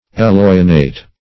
Eloinate \E*loin"ate\, v. t.
eloinate.mp3